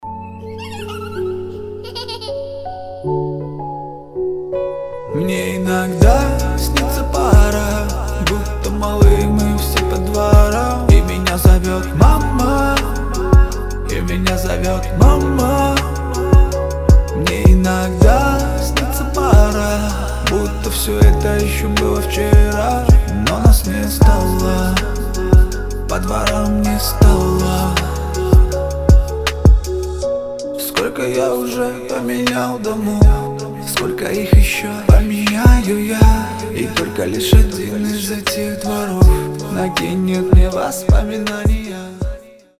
• Качество: 320, Stereo
лирика
душевные
грустные